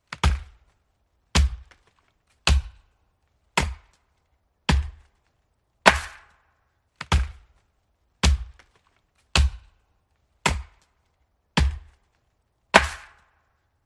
Tiếng Chặt cây, Đốn hạ cây
Thể loại: Tiếng đồ vật
Description: Tiếng đốn cây, tiếng hạ cây, tiếng bổ cây, tiếng cưa cây, tiếng đẽo cây, tiếng chặt gỗ, Chopping Tree Sound Effect... âm thanh “cộc… cộc…” hoặc “rắc… rắc…” vang vọng khi lưỡi rìu, dao rựa hay cưa va chạm mạnh vào thân gỗ, từng nhát dứt khoát làm vỏ và lõi gỗ tách ra. Âm thanh mạnh mẽ, trầm chắc, gợi lên không khí lao động lâm nghiệp hoặc khai thác gỗ ngoài tự nhiên.
tieng-chat-cay-don-ha-cay-www_tiengdong_com.mp3